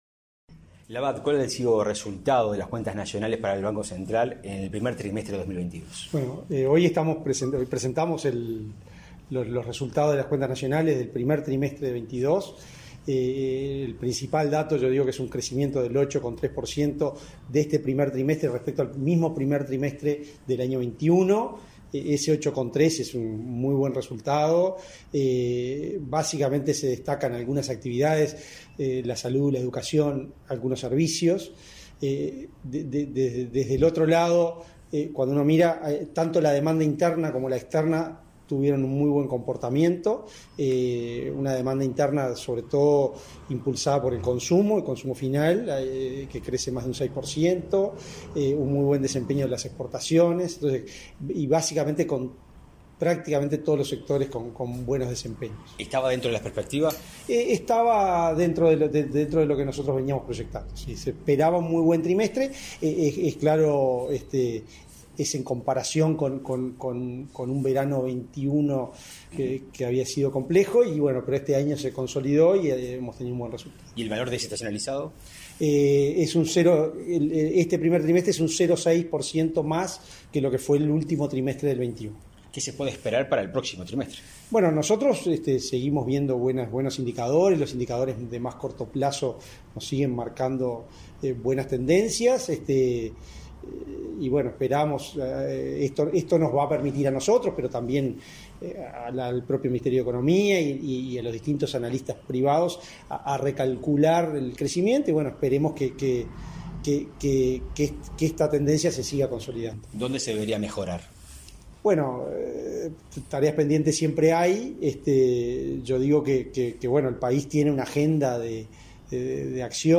Entrevista al presidente del BCU, Diego Labat